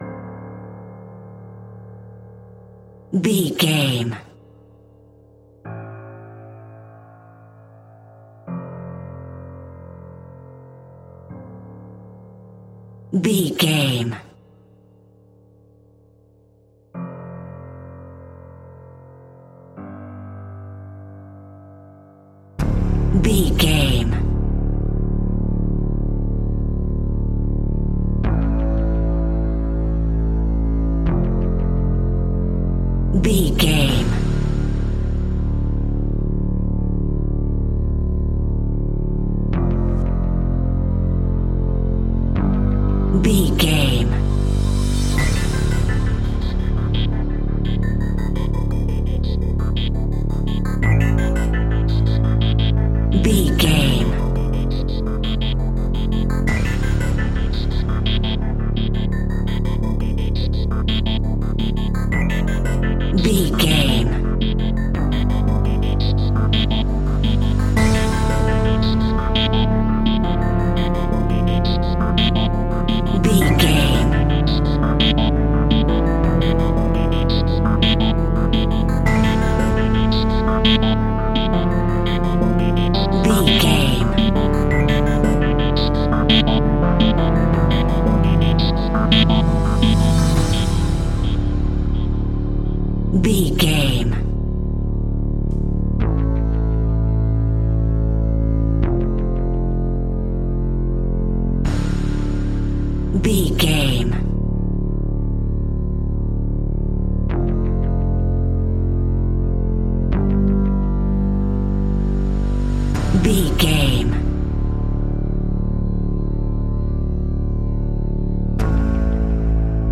Aeolian/Minor
WHAT’S THE TEMPO OF THE CLIP?
ominous
dark
haunting
eerie
piano
strings
synthesiser
tense
ticking
electronic music
Horror Pads
Horror Synths